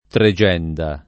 tregenda [ tre J$ nda ] s. f.